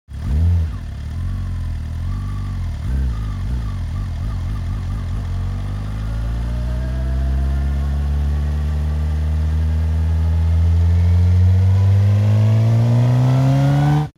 دانلود آهنگ موتور 7 از افکت صوتی حمل و نقل
جلوه های صوتی
دانلود صدای موتور 7 از ساعد نیوز با لینک مستقیم و کیفیت بالا